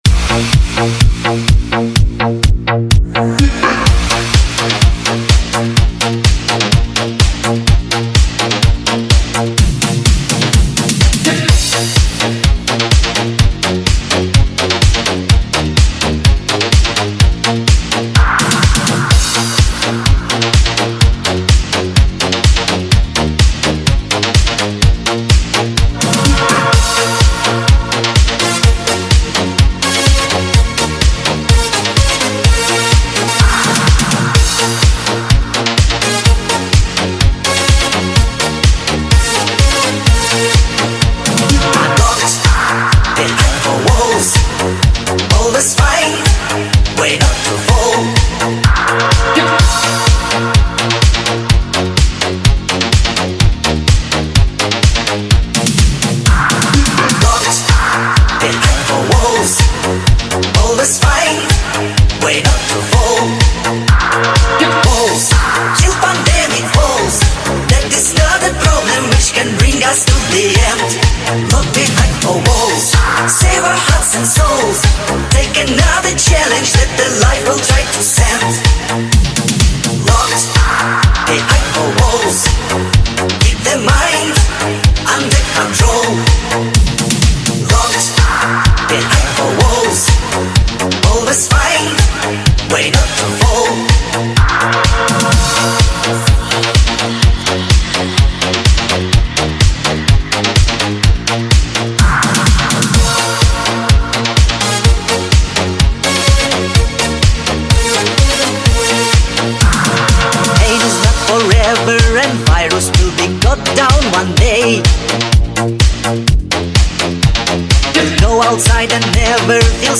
Ретро музыка